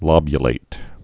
(lŏbyə-lātĭd) also lob·u·late (-lāt)